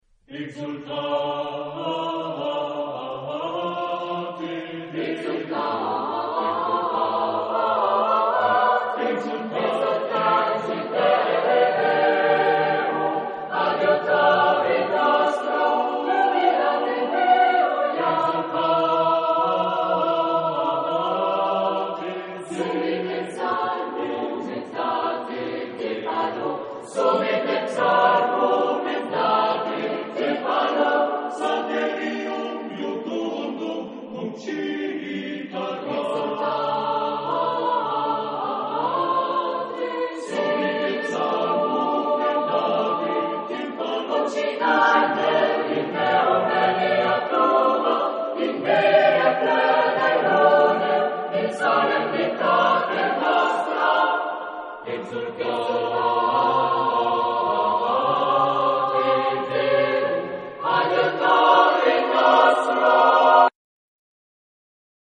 Genre-Stil-Form: Psalm ; geistlich
Instrumente: Tamburin (1)